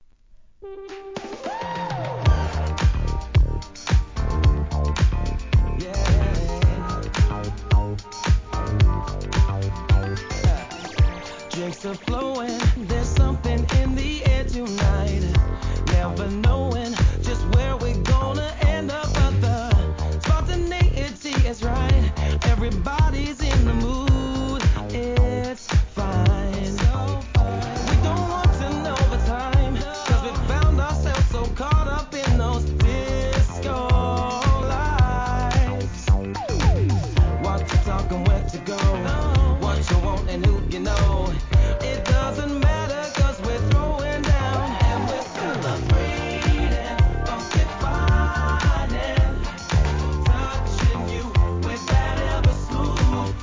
HIP HOP/R&B
人気のUKスムース・ダンサー!!